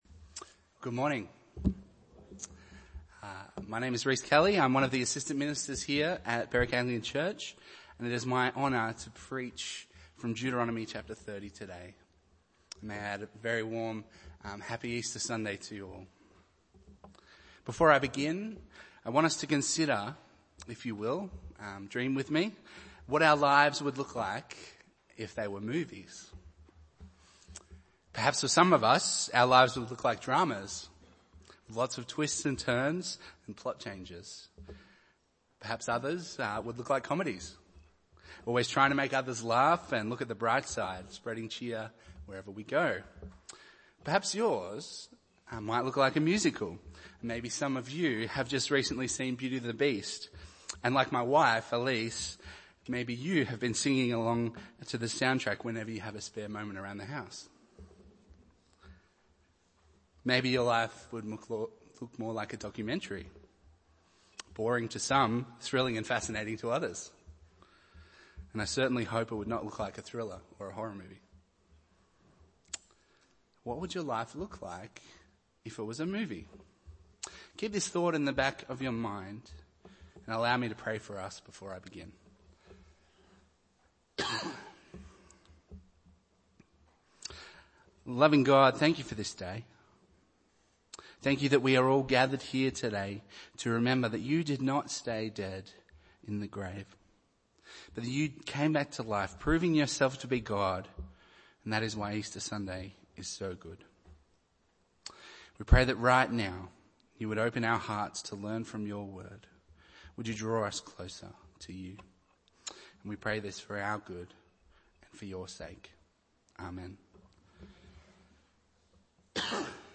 Bible Text: Deuteronomy 30:1-20 | Preacher